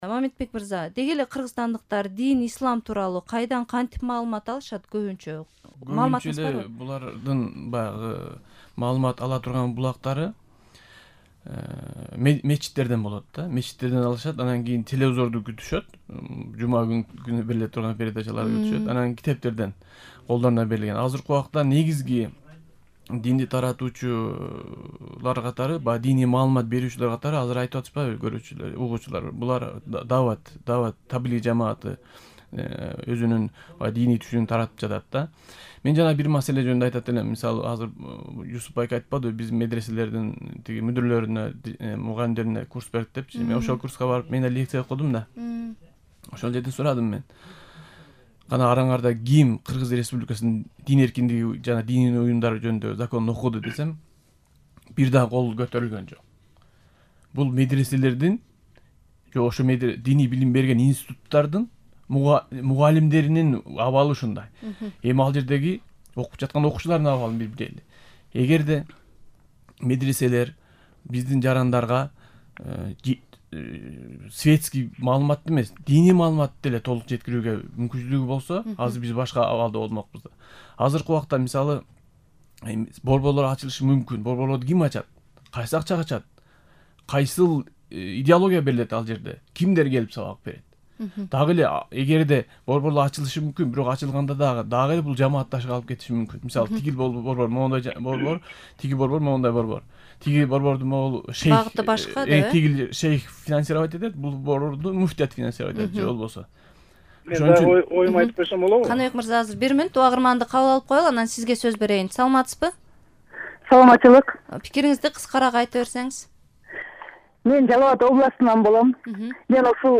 Дин тууралуу талкуу (1-бөлүк)